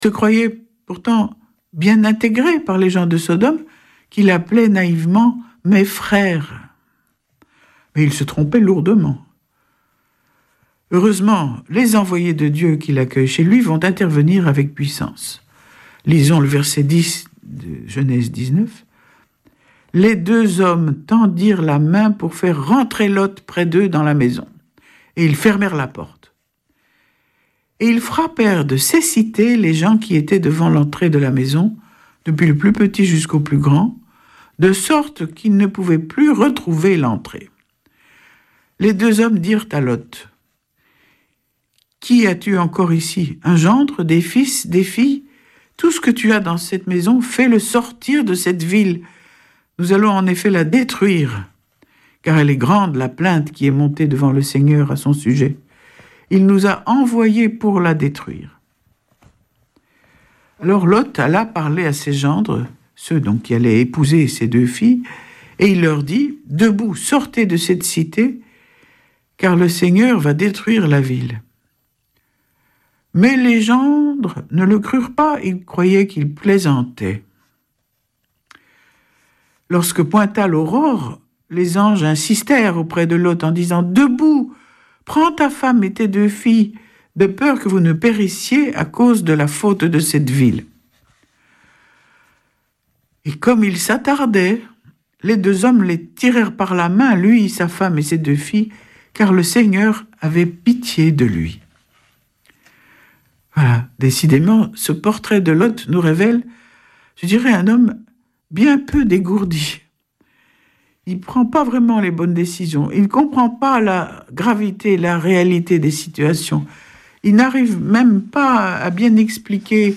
Vêpres de Saint Sernin du 06 juil.